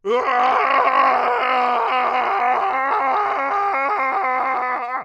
Longscream.wav